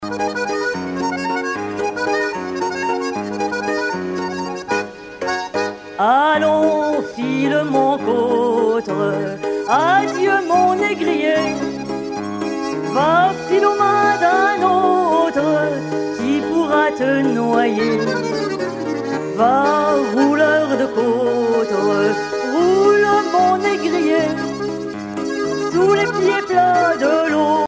Genre strophique
Chansons de la soirée douarneniste 88